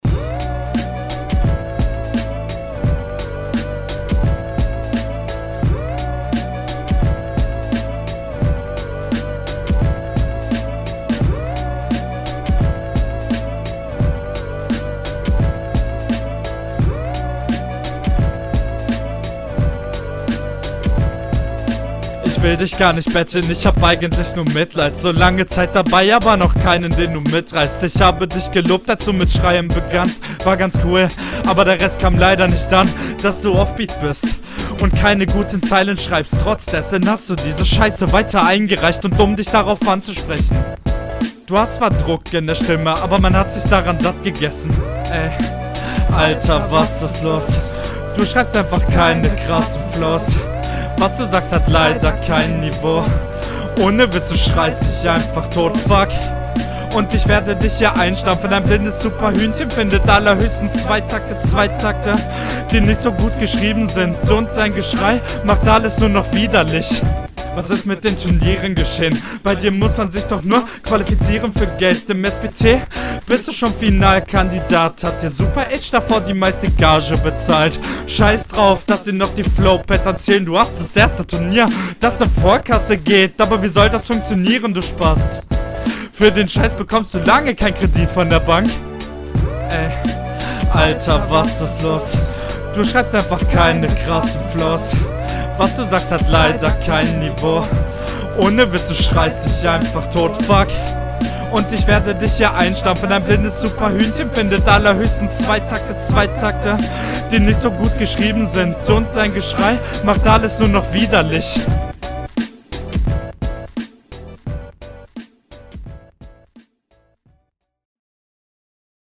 Versuch mal die Atmer bisschen leiser zu machen.